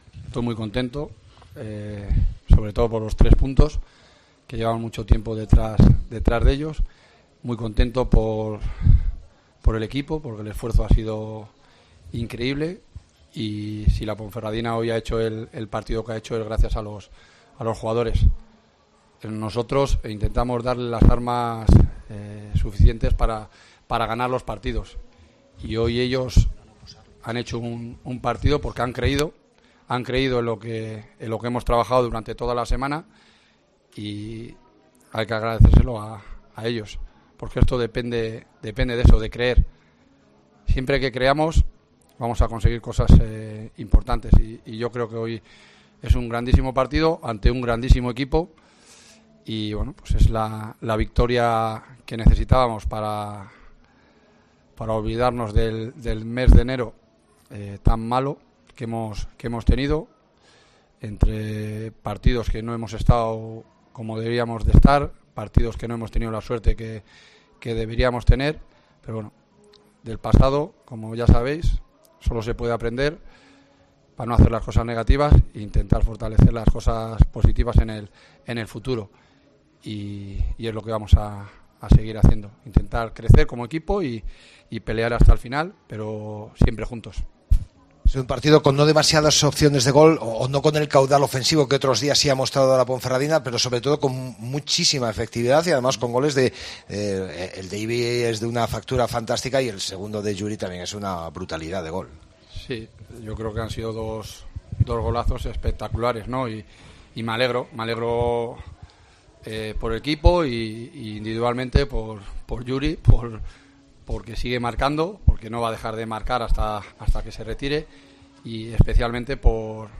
POSTPARTIDO
Escucha aquí las palabras del míster de la Deportiva Ponferradina, Jon Pérez Bolo, tras la victoria 3-1 ante el Huesca